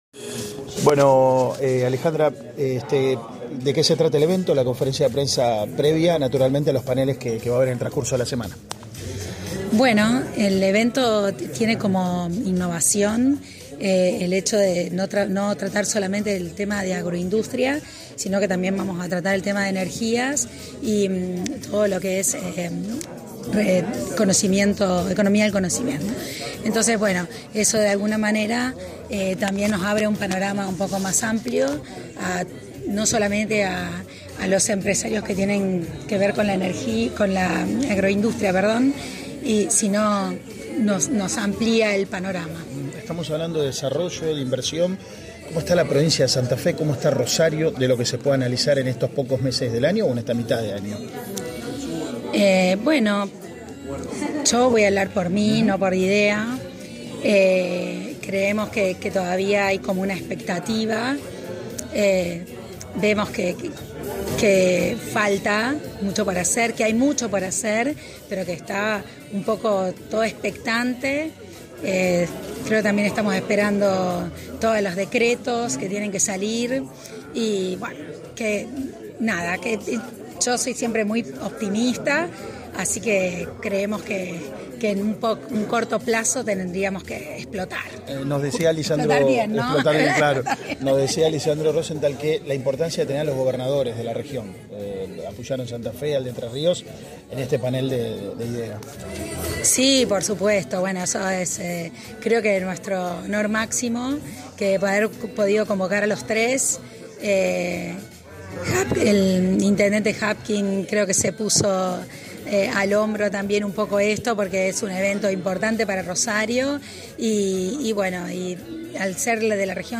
en contacto con el móvil de Cadena 3 Rosario, en Siempre Juntos.